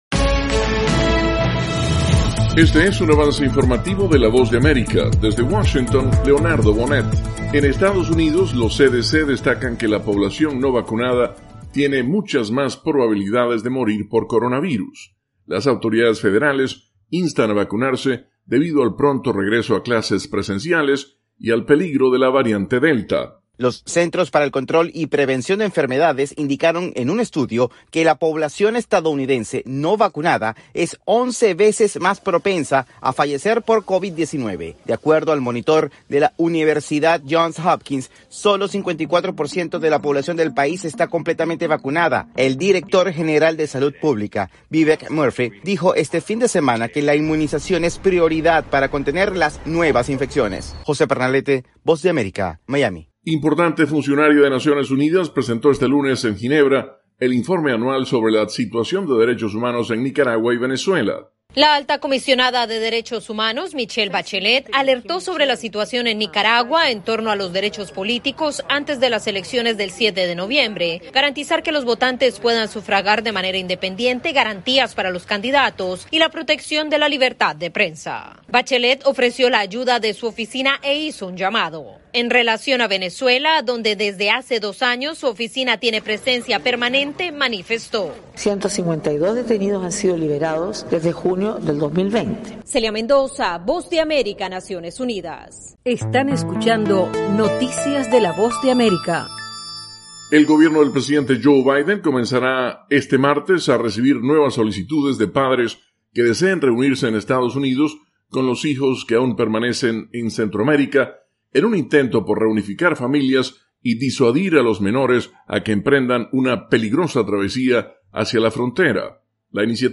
AVANCE INFORMATIVO 7PM